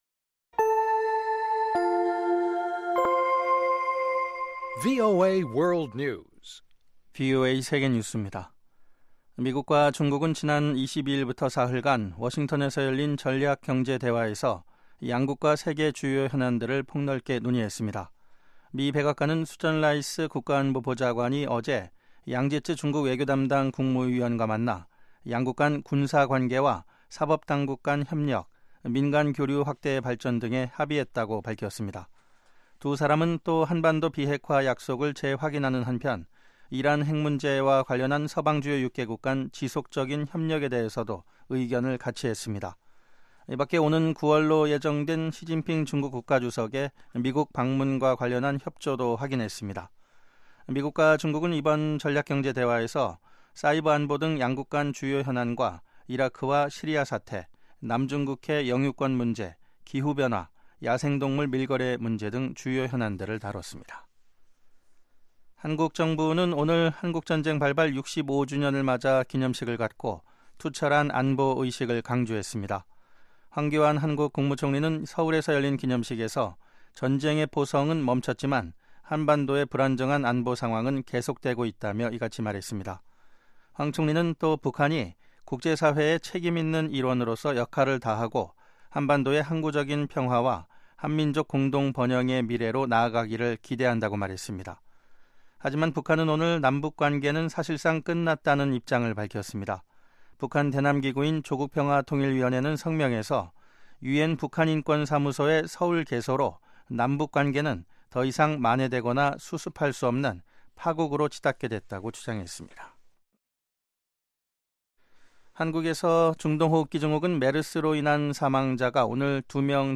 VOA 한국어 방송의 간판 뉴스 프로그램 '뉴스 투데이' 2부입니다.